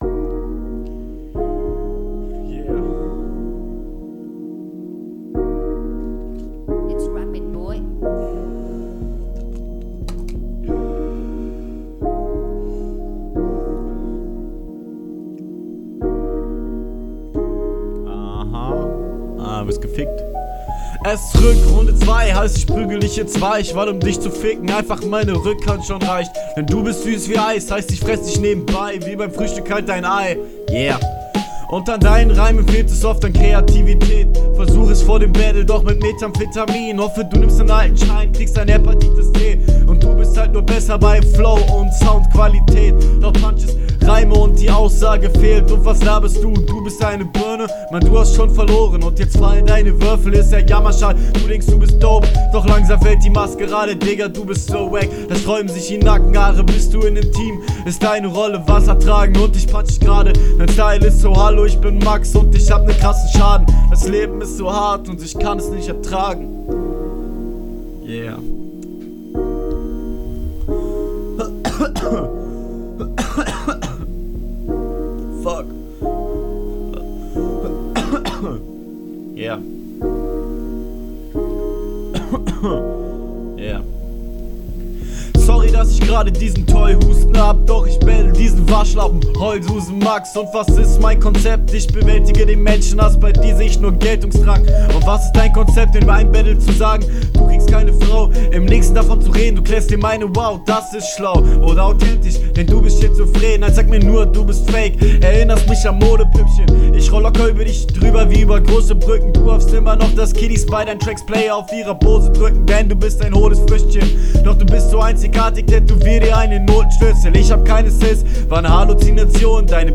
direkt fällt schonmal auf, dass du offenbar nicht immer gleichnah am mikro dran bist. eigentlich …